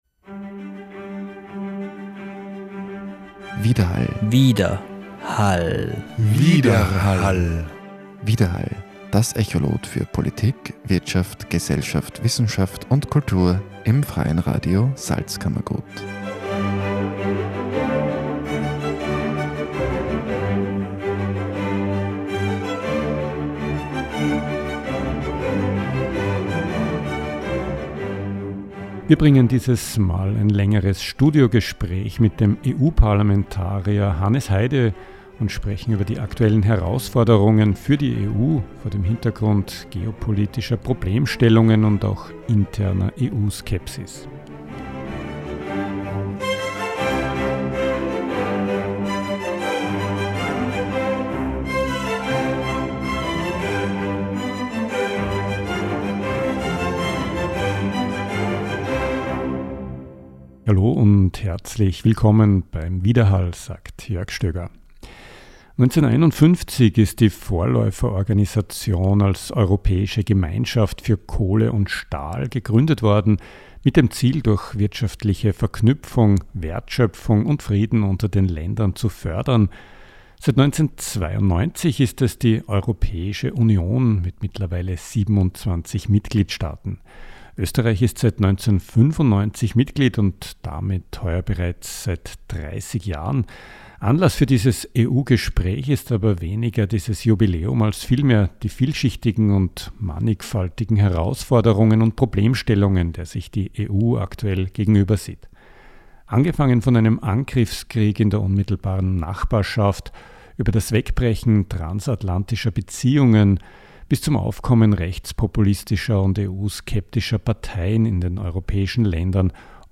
Europaabgeordneter Hannes Heide über das Selbstverständnis und aktuelle Herausforderungen der EU – Studiogespräch mit dem Europa-Parlamentarier vor dem Hintergrund geopolitischer Problemstellungen wie Krieg und diplomatischer Krisen sowie inner-europäischer Anti-EU-Politik durch rechts-populistische Parteien. Was tun gegen wankendes Selbstvertrauen und steigende EU-Skepsis?